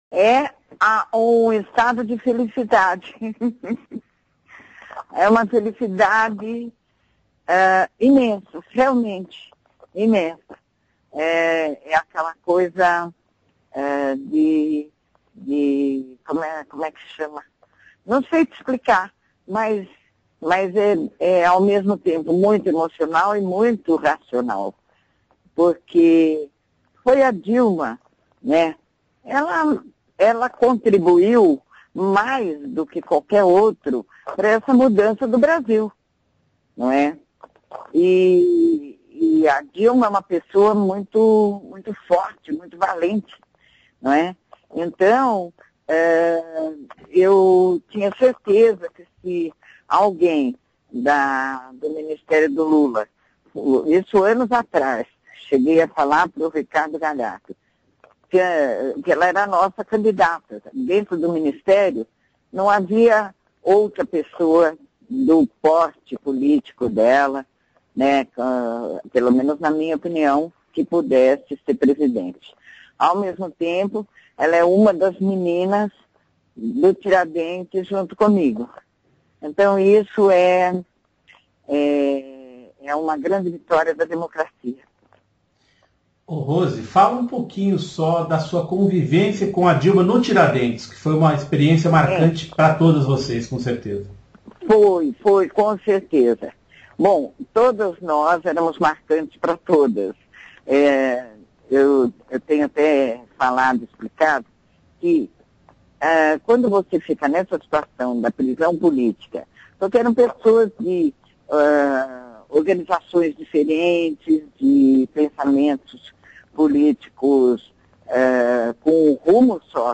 por telefone, de Brasília, com seu tradicional bom humor